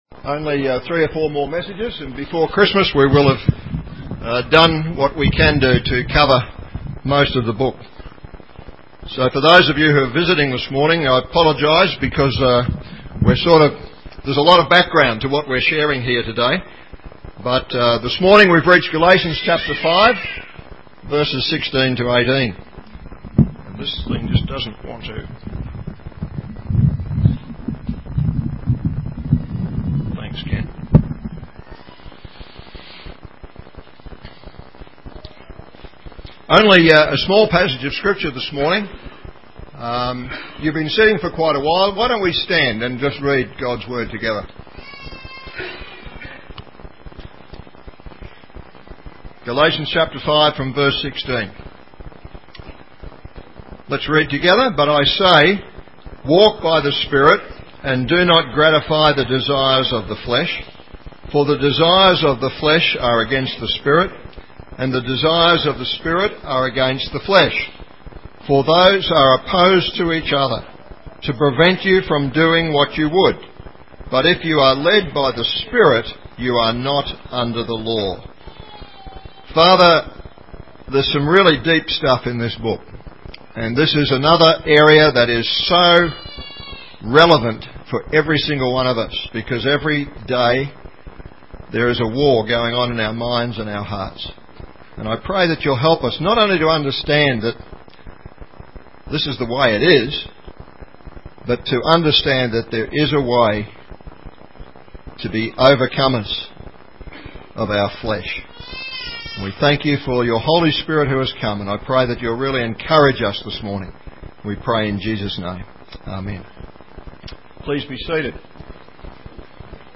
Galatians 5:16-18 Listen to the sermon here.